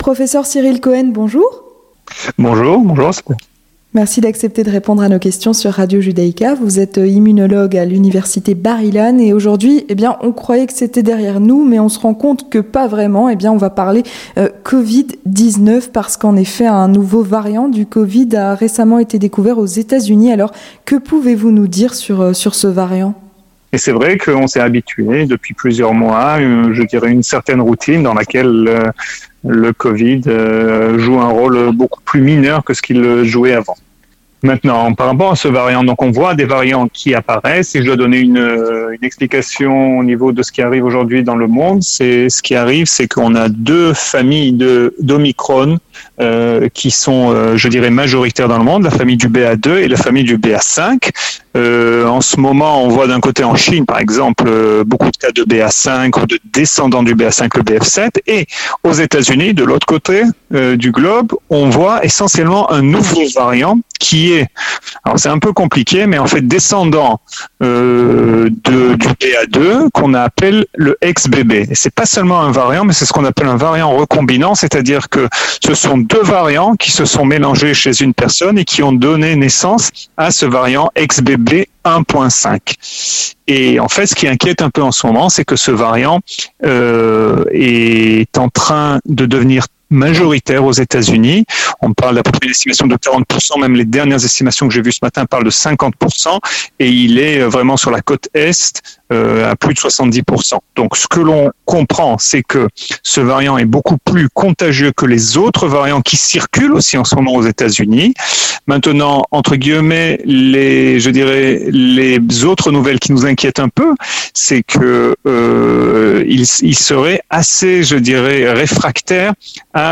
Entretien du Grand Journal